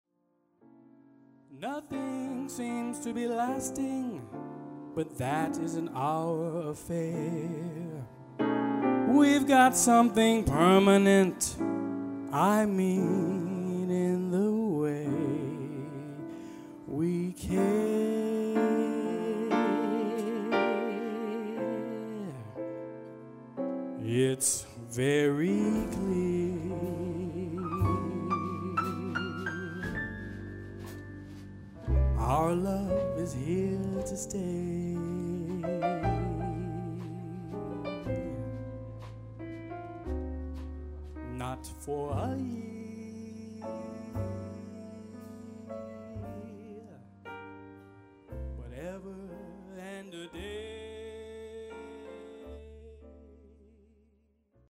A throw back to Jazz Crooning!